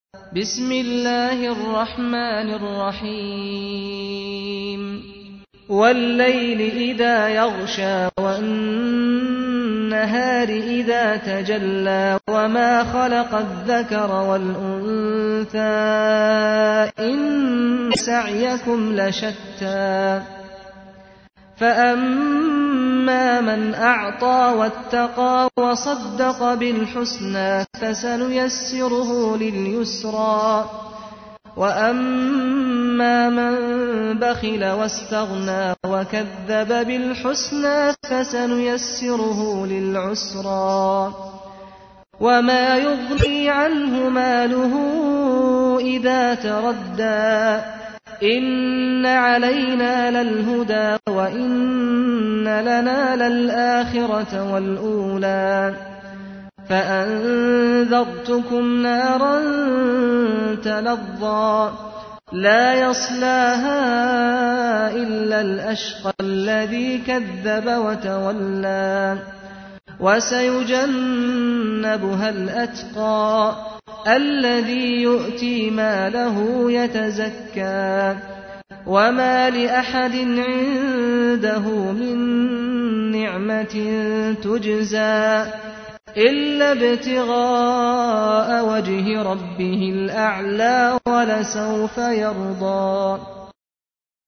تحميل : 92. سورة الليل / القارئ سعد الغامدي / القرآن الكريم / موقع يا حسين